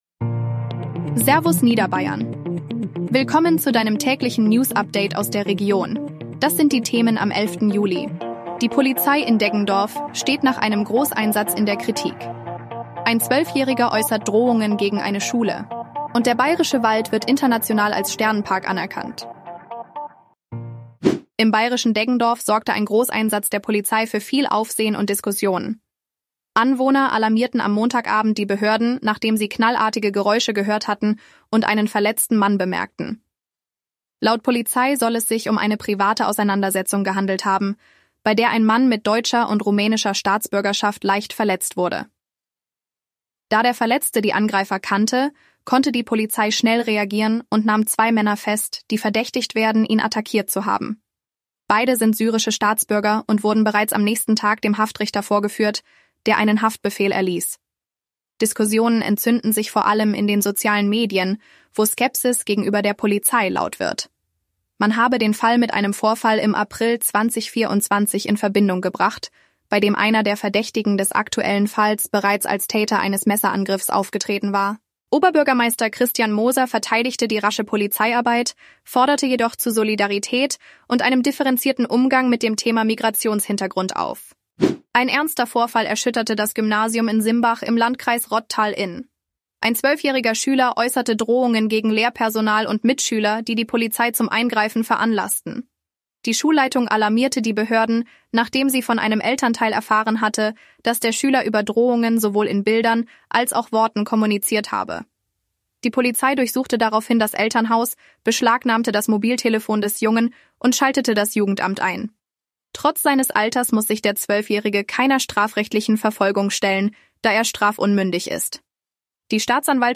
Tägliche Nachrichten aus deiner Region
Dein tägliches News-Update